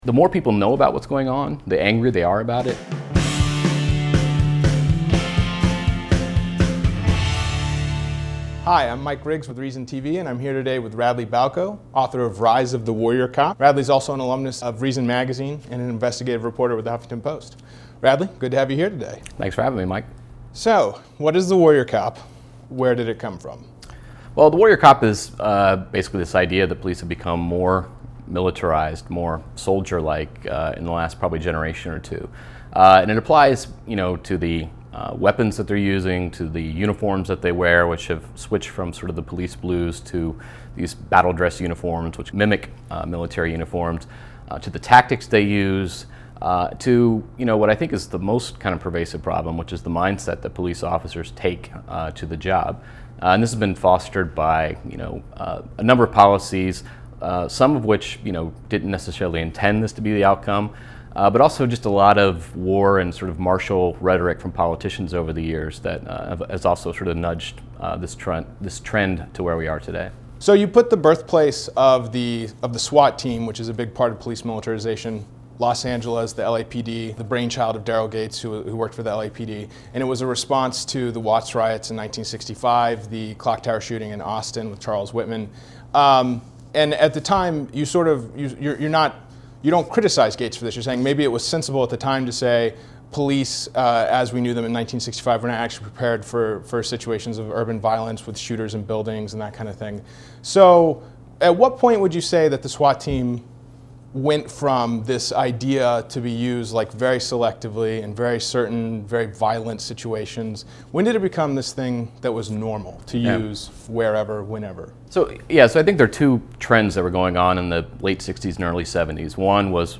Balko, author of the new book Rise of the Warrior Cop: The Militarization of America's Police Forces, sat down with ReasonTV to discuss the book, the growth and development of SWAT forces, and how the drug war has fostered an "us against them" mentality within police departments.